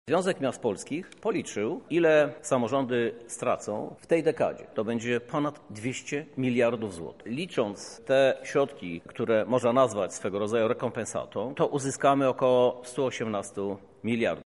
Wśród problemów które pojawiły się w związku ze zmianami podatkowymi prezydent Lublina Krzysztof Żuk wskazuje na coraz mniejsze wpływy środków do kasy miejskiej.